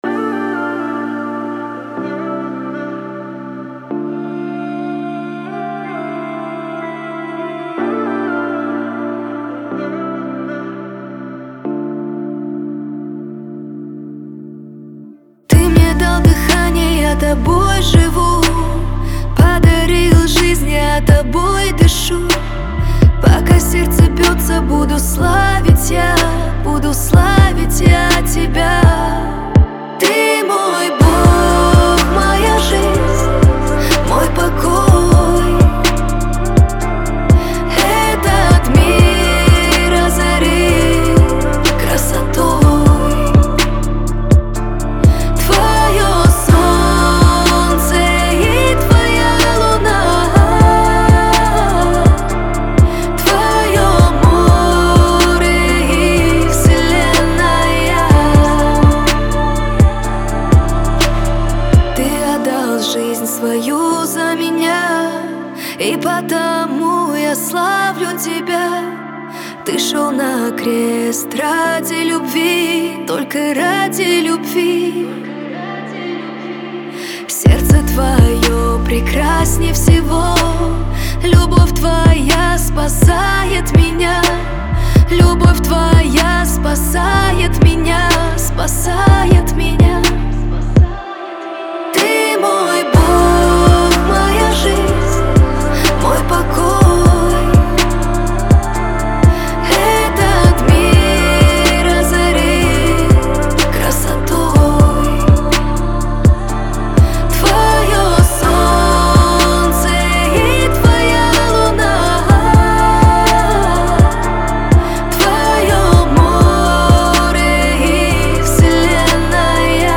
это глубокая и эмоциональная композиция в жанре поп-рок.
а также гармоничные инструментальные аранжировки.